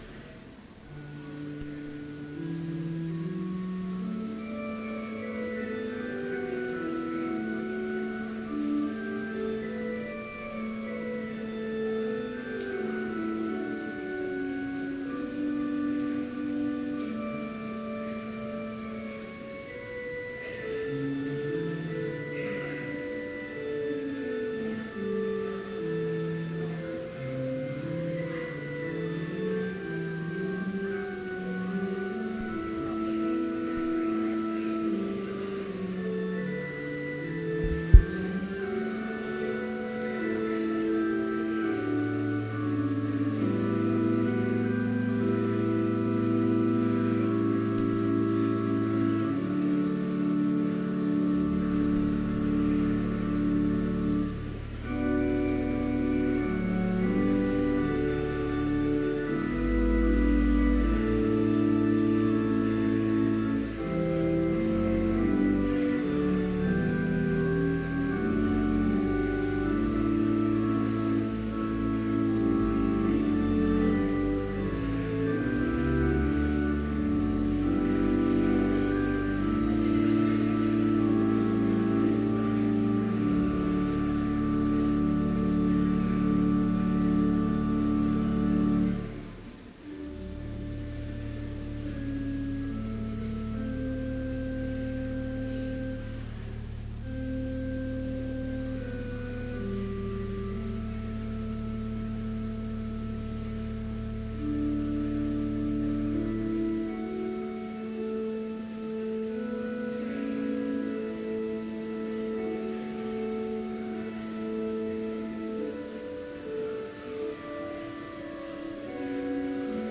Chapel service held on May 12, 2015, in Trinity Chapel
Complete service audio for Chapel - May 12, 2015